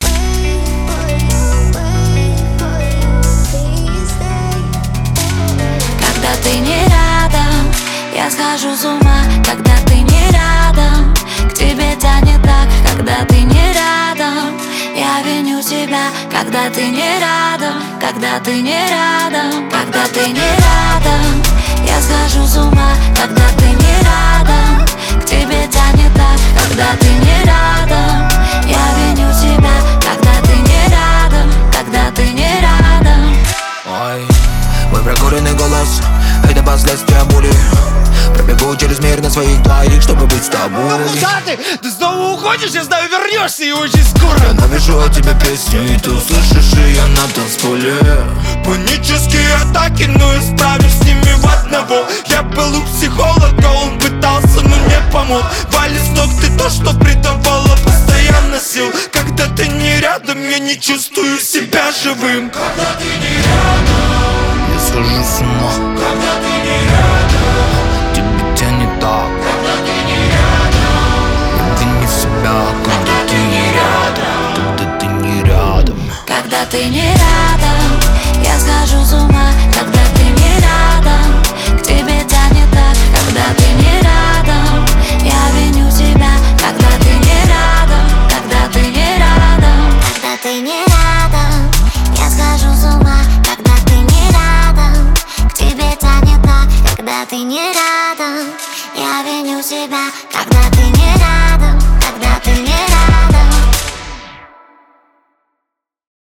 Жанр: Русская музыка